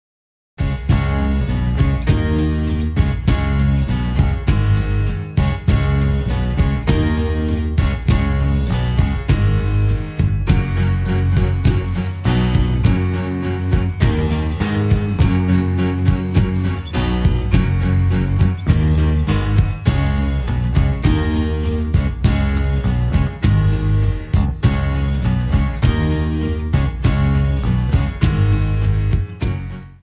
[instrumental]
Studio de son Québec